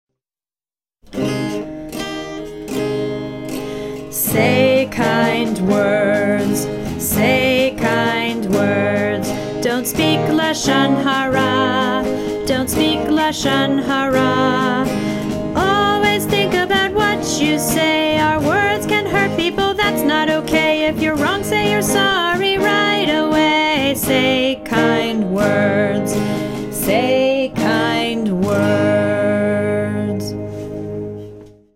2) SONG: (tune: Three Blind Mice)